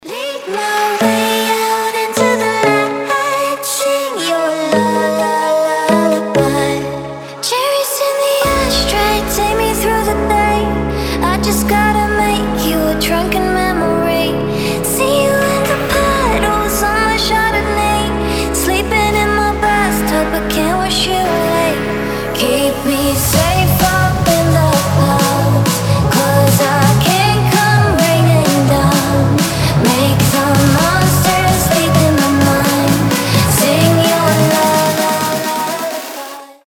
• Качество: 320, Stereo
женский вокал
Electronic
EDM
нарастающие
future house
красивый женский голос
нежные